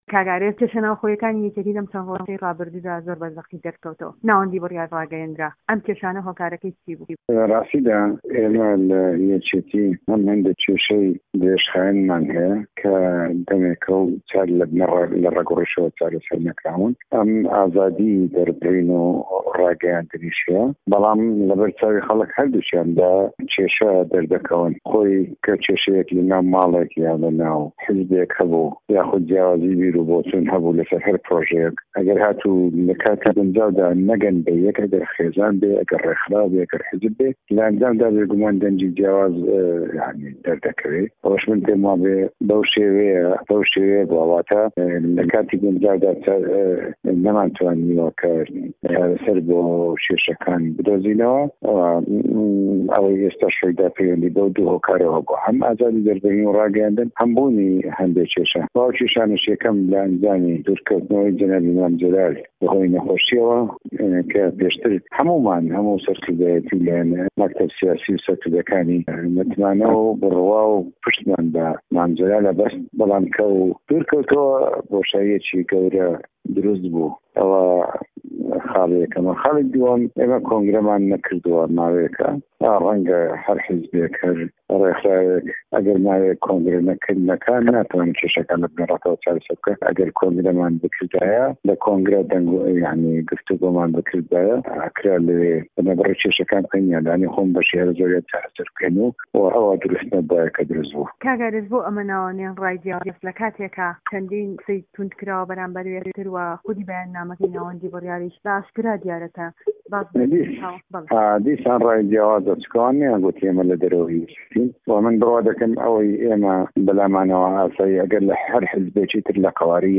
وتووێژ لەگەڵ ئارێز عەبدوڵڵا